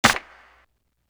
Neighborhood Watch Snare.wav